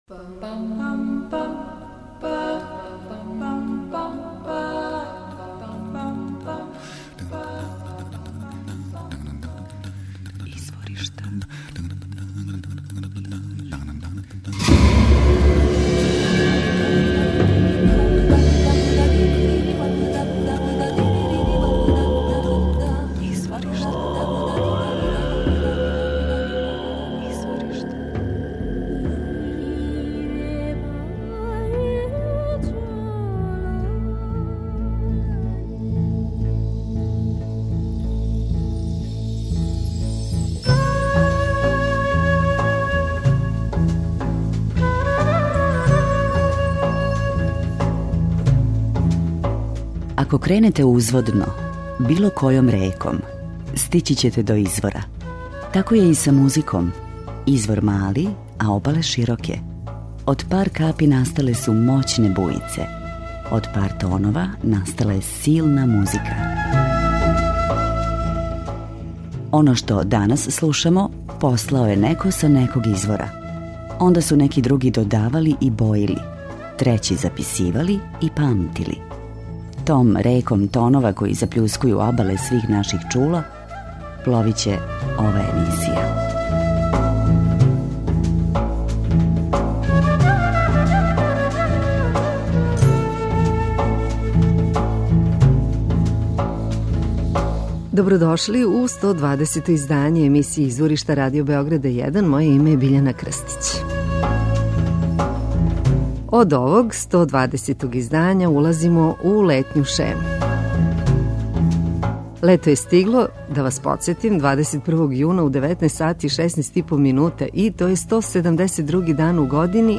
Направили смо избор из свих досадашњих емисија. Дружићемо се са музичарима чију музику дефинишу у оквирима world music.
Уживајте уз значајна имена светске музичке сцене.